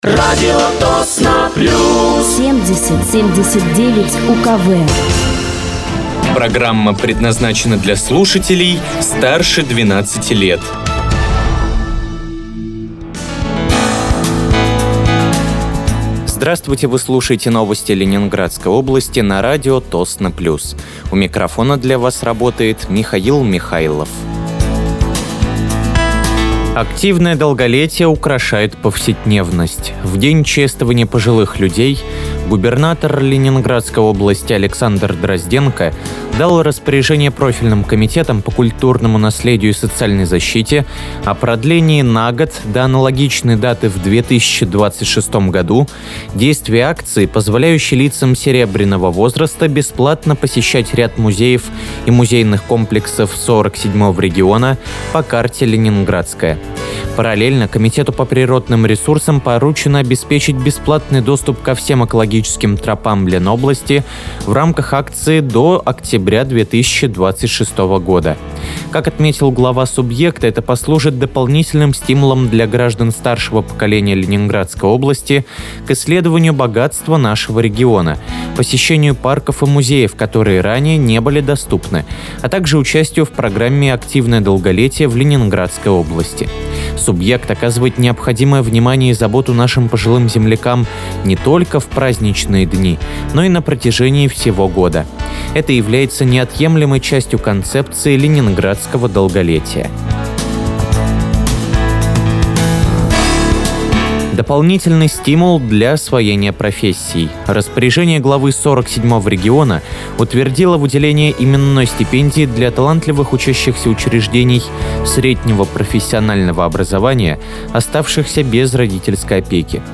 Вы слушаете новости Ленинградской области от 08.10.2025 на радиоканале «Радио Тосно плюс».